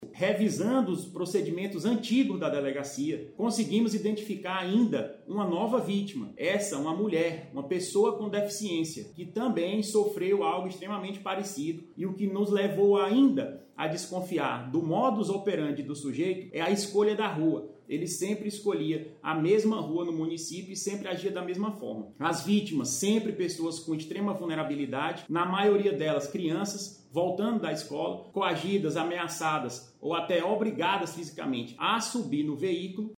SONORA02_DELEGADO.mp3